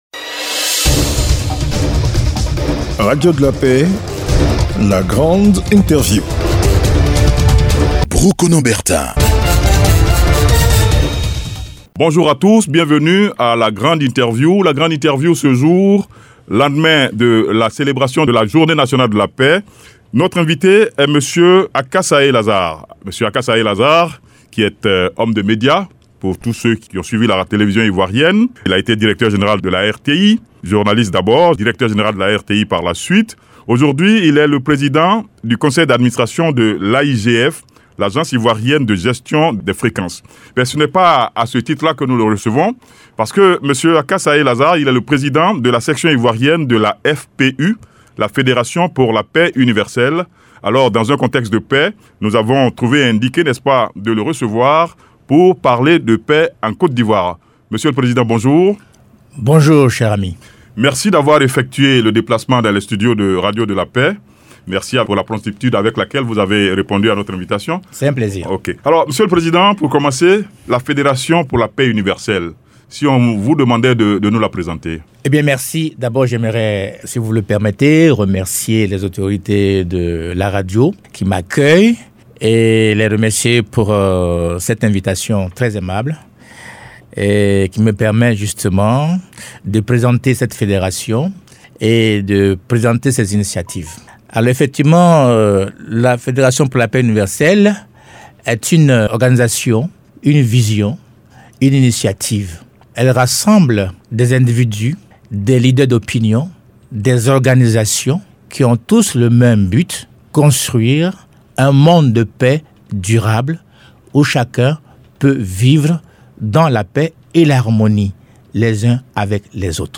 SEM Richard BELL, Ambassadeur des États-Unis en Côte d’Ivoire, était l’invité de la Grande Interview de la Radio de Paix le 25 avril 2020.